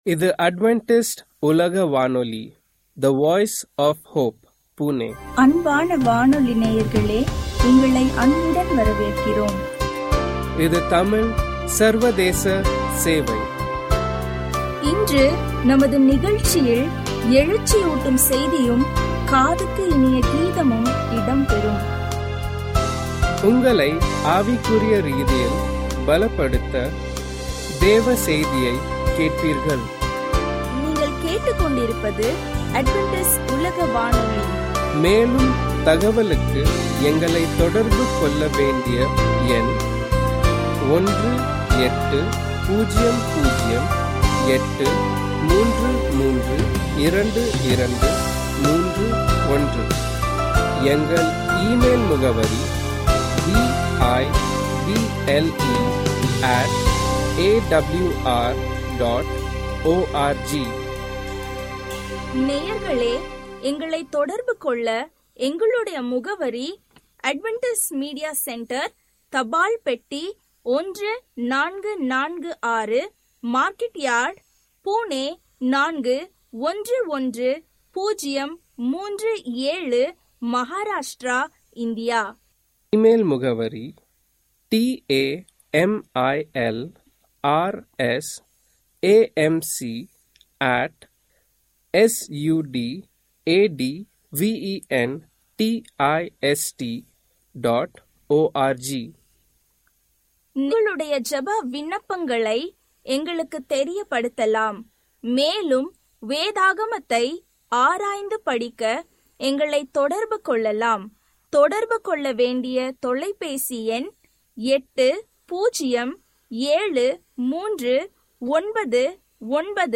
Tamil radio program from Adventist World Radio